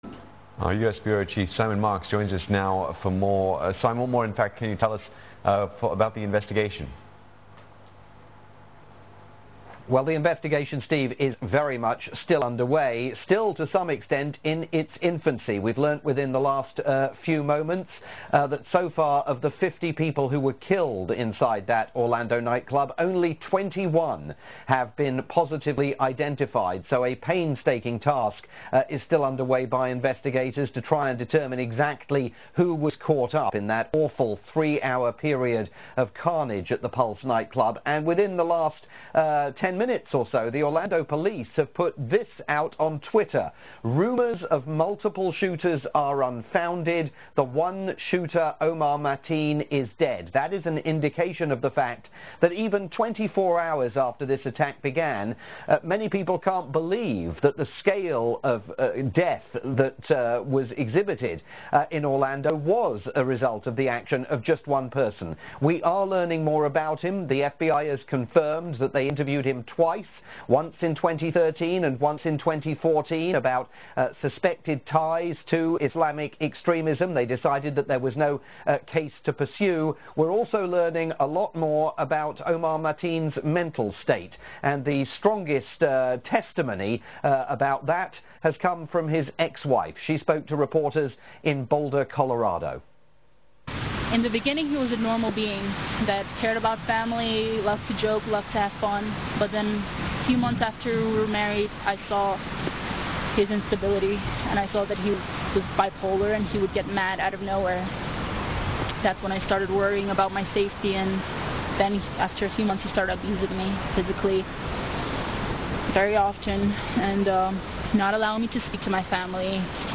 reported the latest events live for Channel NewsAsia, Singapore's 24-hour TV news channel.